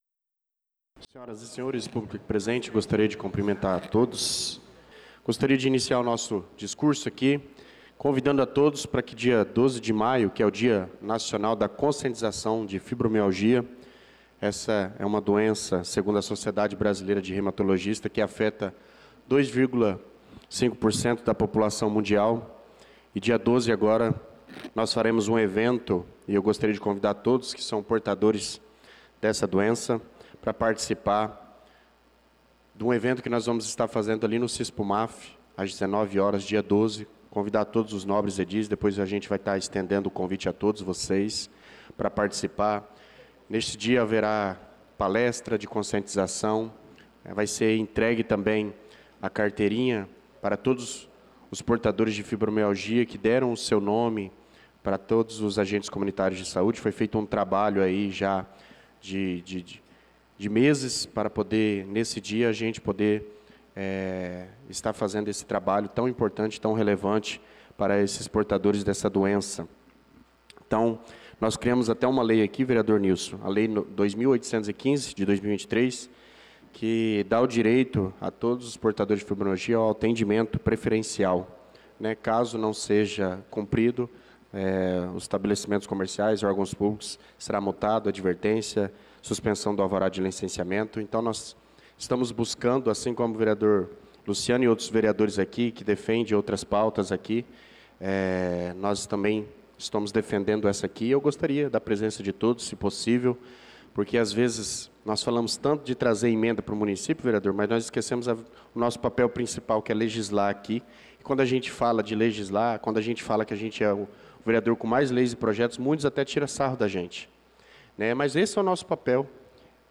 Pronunciamento do vereador Douglas Teixeira na Sessão Ordinária do dia 05/05/2025